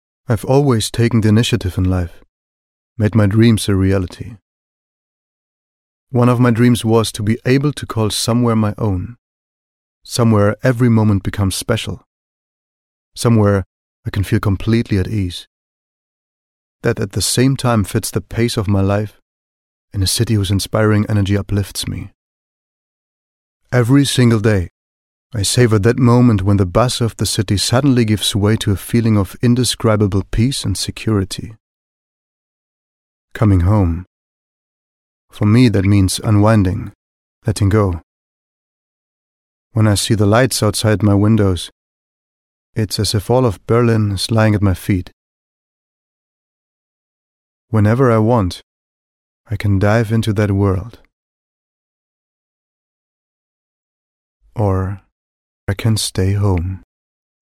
Werbung - Stimme